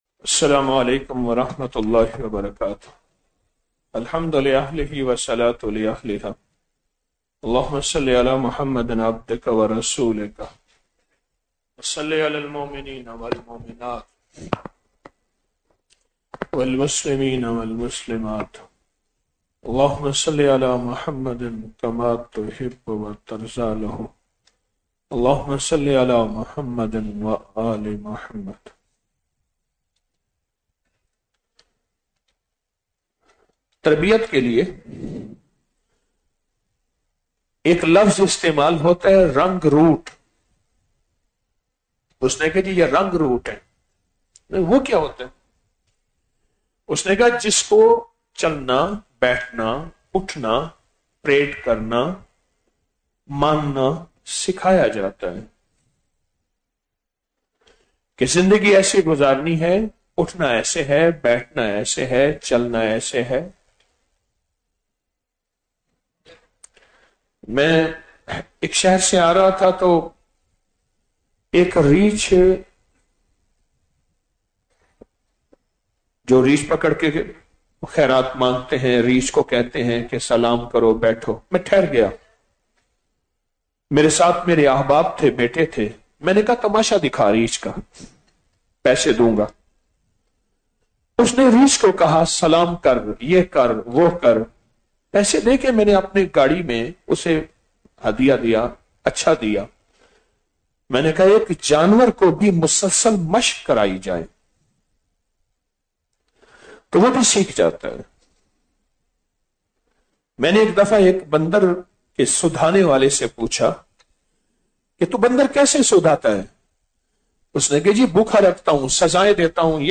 16 رمضان المبارک بعد نماز تراویح - 05 مارچ 2026ء